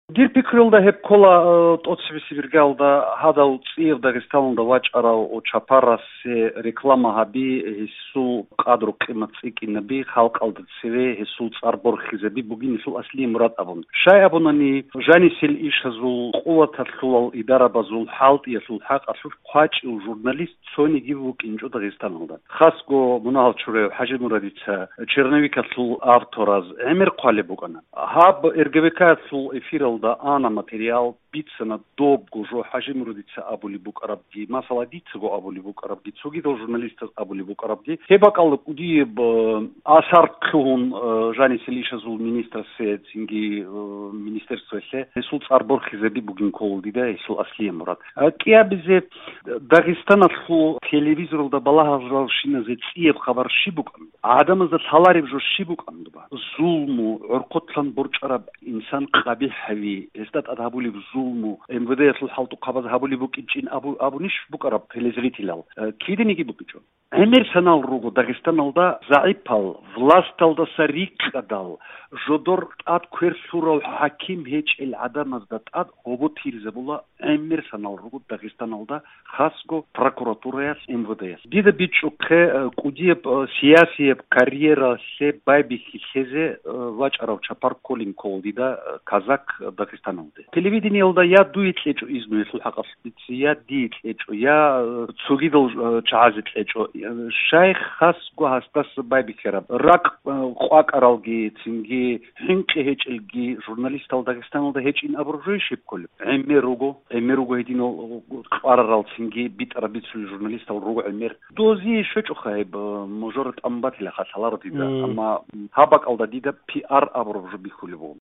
тохтур, жамгIияв хIаракатчи